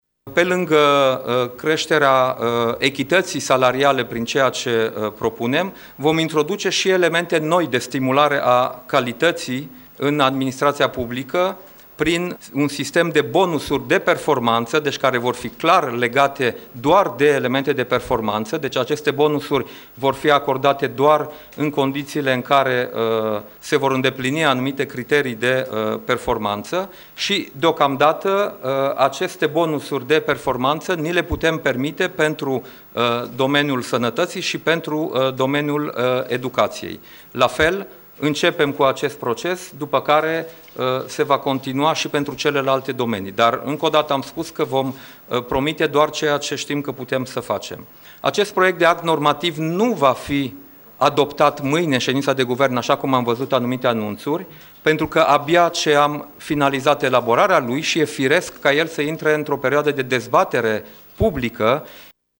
Într-o declarație de presă susținută cu puțin timp în urmă la Palatul Victoria, premierul Dacian Cioloș a explicat de ce creșterile salariale din sănătate au fost acordate cu prioritate, în proiectul de ordonanță privind creșterea salariilor bugetarilor și a arătat care sunt criteriile care sunt avute în vedere în noul act normative: